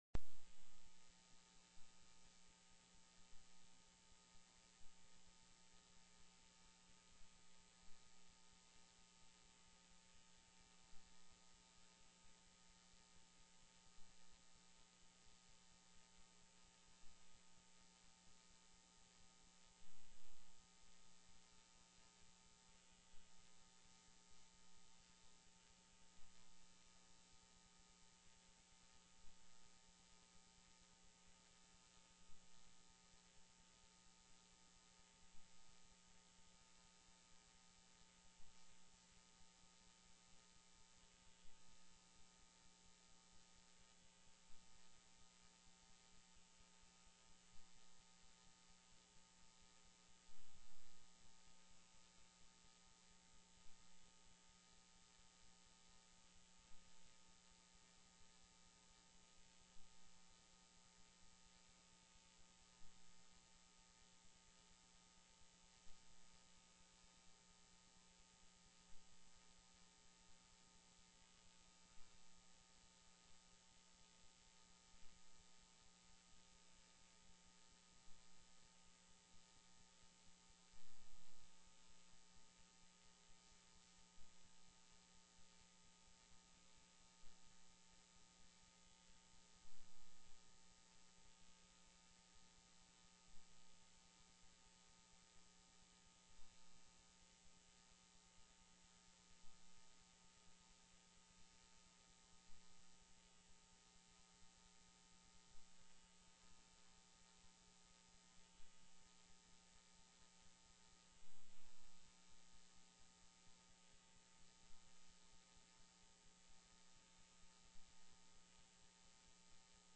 PRESENT VIA TELECONFERENCE